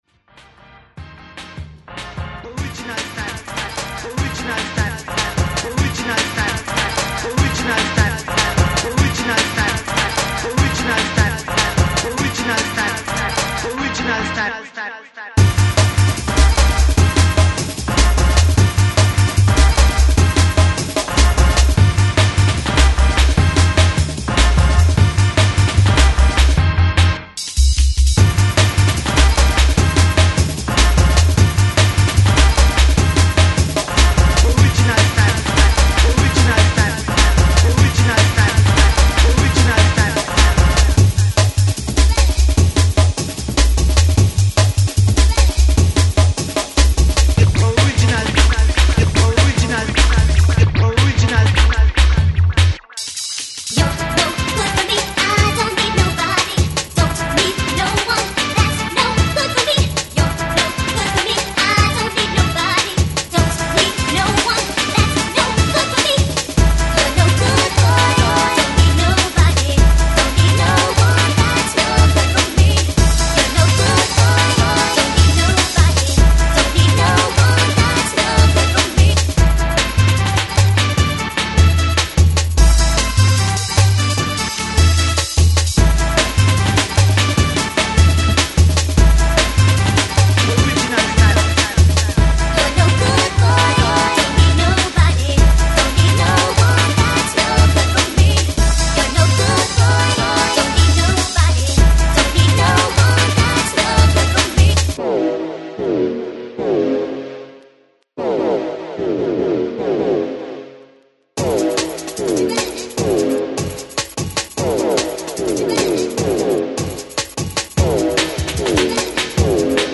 A return to the original sound of Hardcore/Jungle Rave